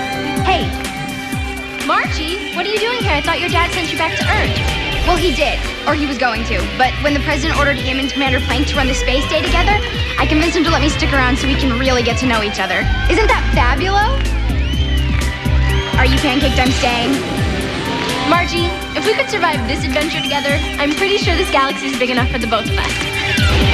Margie and Zenon talking at the End of "Zenon the Zequel"